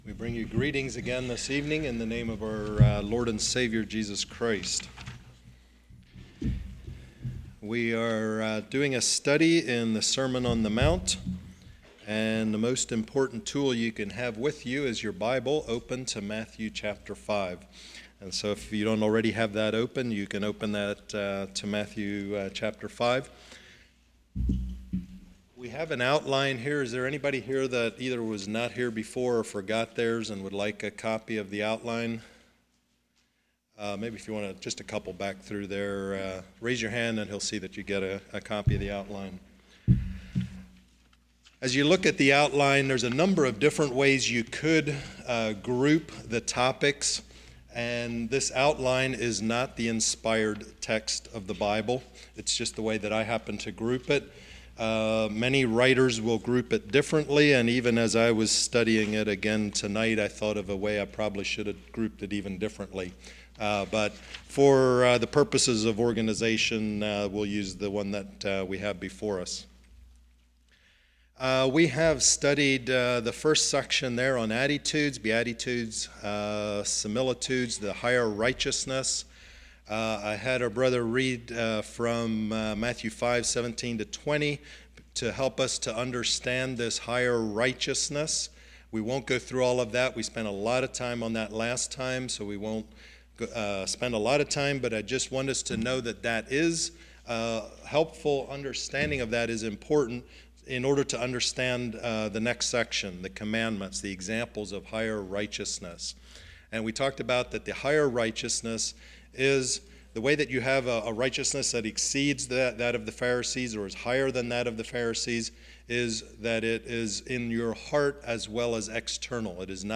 Passage: Matthew 5:17-20 Service Type: Winter Bible Study righteousness « Sermon on the Mount